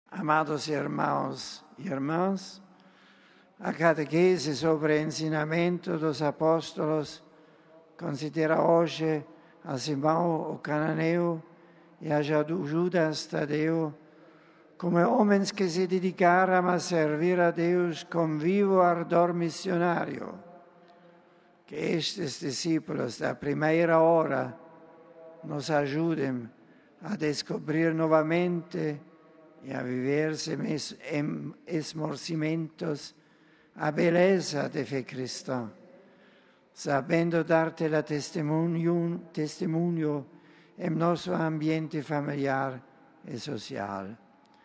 Cidade do Vaticano, 11 out (RV) - Bento XVI invoca força, clareza e coragem ao testemunhar a fé cristã, falando aos milhares de fiéis, peregrinos e turistas provenientes de várias partes do mundo, reunidos esta manhã, na Praça São Pedro, para a Audiência Geral.
Concluída a catequese, o Papa fez uma síntese da mesma em várias línguas.